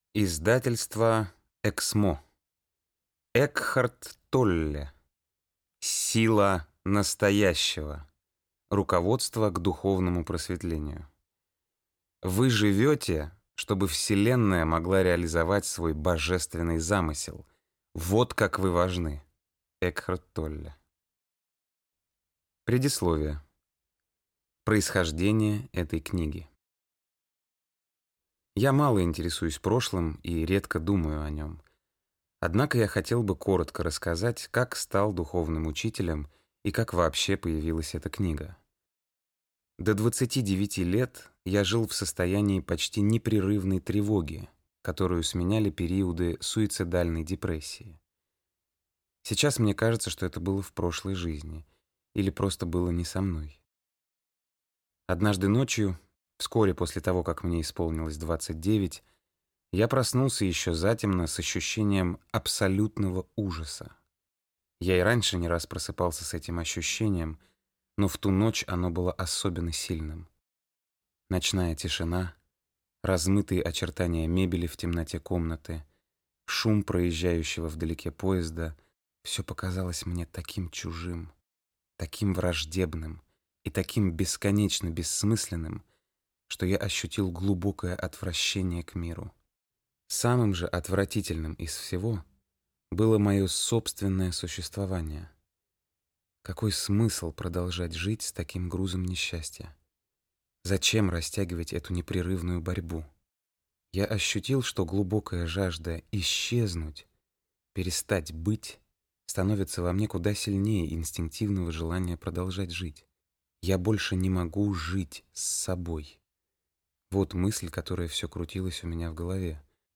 Аудиокнига Сила настоящего. Руководство к духовному просветлению | Библиотека аудиокниг
Руководство к духовному просветлению Автор Экхарт Толле Читает аудиокнигу Никита Ефремов.